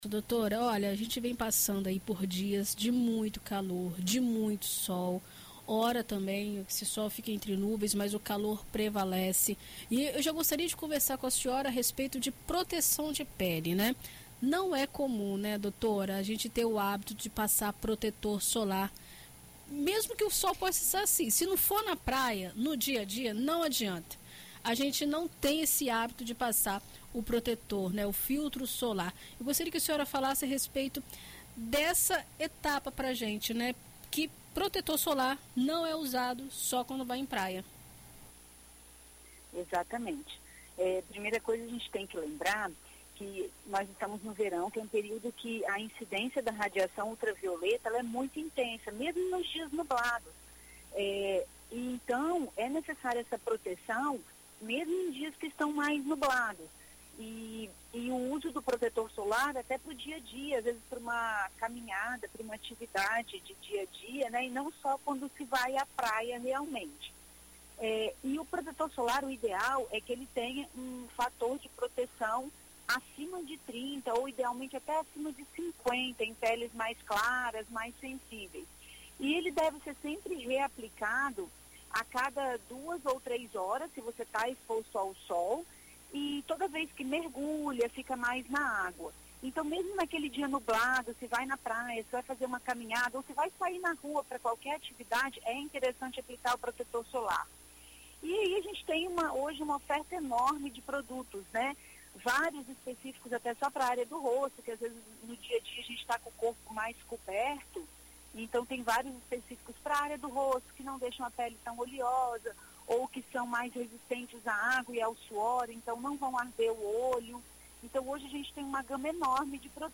Em entrevista à BandNews FM ES nesta sexta-feira